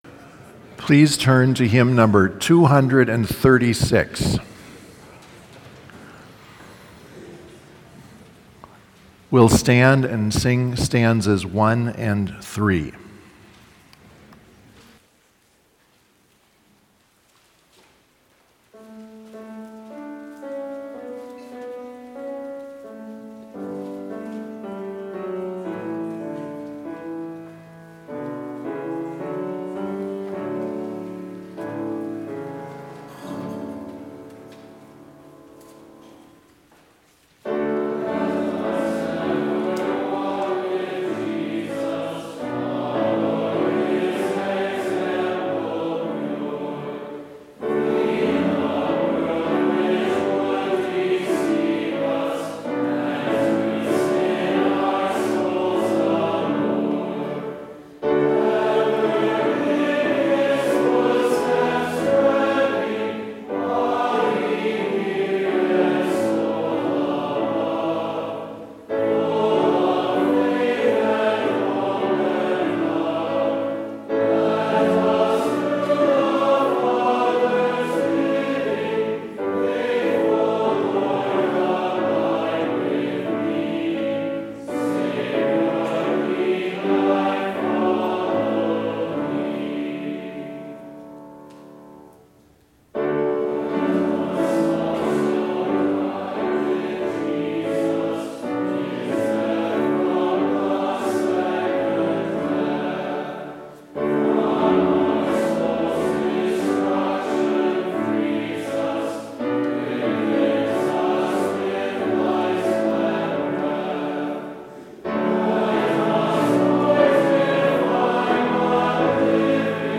Complete service audio for Chapel - March 15, 2022